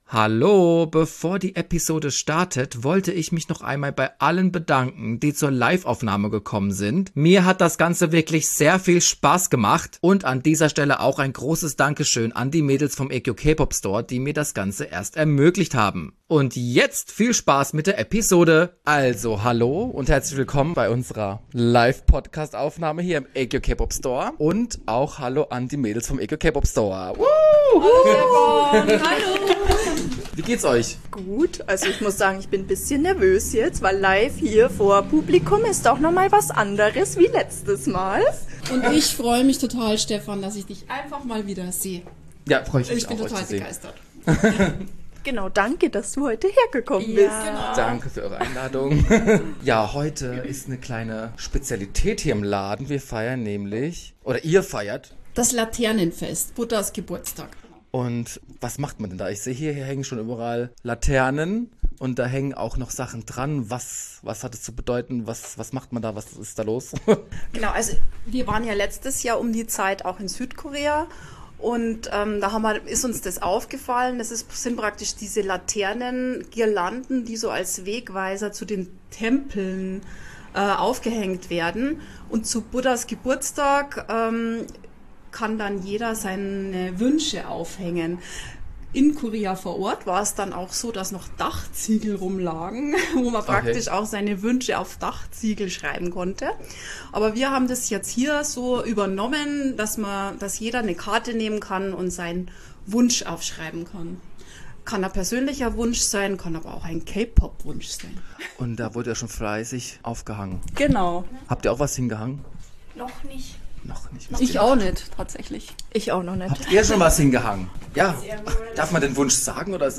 #11 Ab nach Südkorea (Live @ Aegyo K-Pop Store) ~ We Got The Sauce Podcast
Gemeinsam haben wir über unsere Erfahrungen gesprochen und Hot Spots verraten.